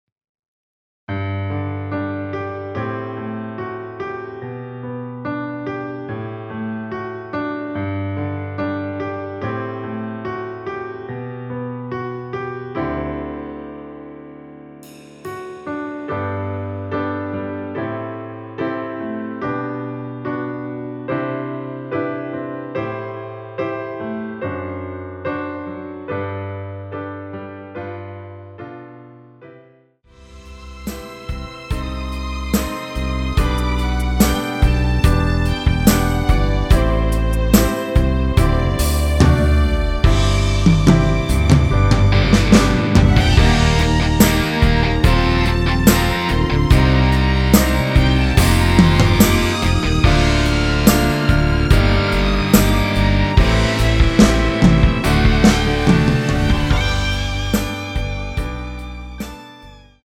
원키에서(+4)올린 MR입니다.
앞부분30초, 뒷부분30초씩 편집해서 올려 드리고 있습니다.
중간에 음이 끈어지고 다시 나오는 이유는